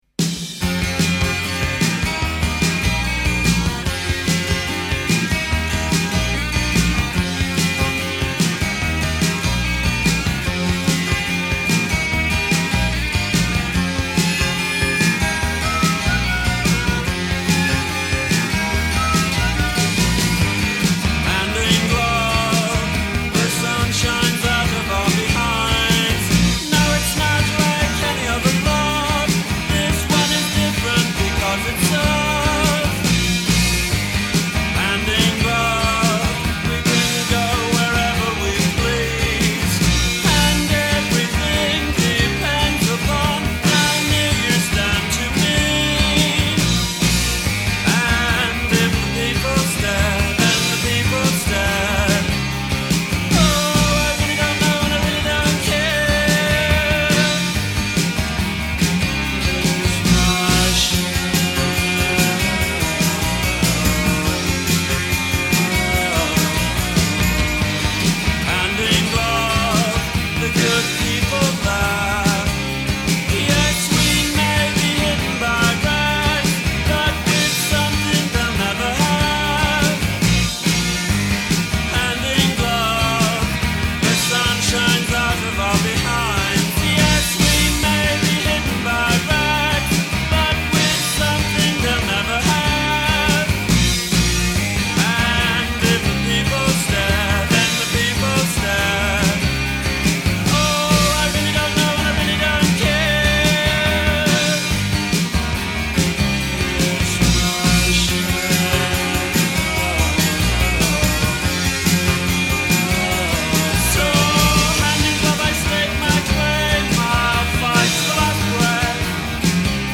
left other British rock bands and teamed up